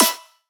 normal-hitwhistle.wav